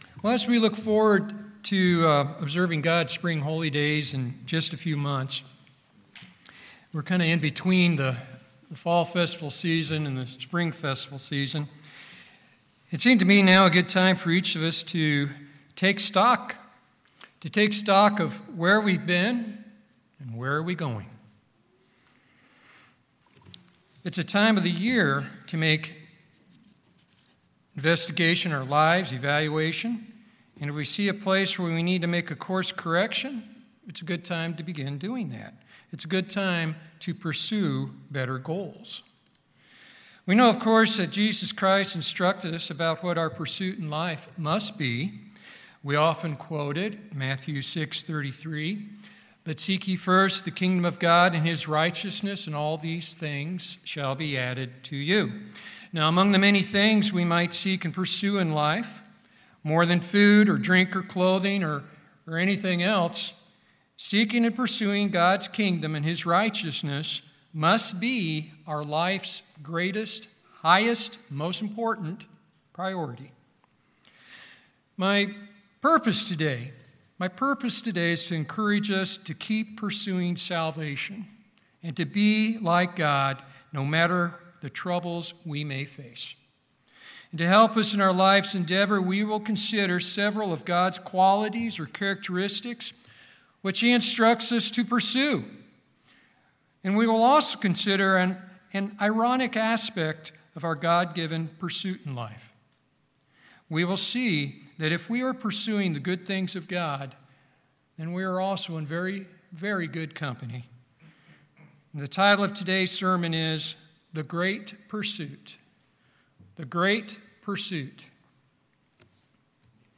Seeking and pursuing God's Kingdom and His righteousness must be our life's greatest priority. In this sermon we will review several of God's qualities or characteristics which He instructs us to pursue, and we will also consider an ironic aspect our God-given pursuit in life.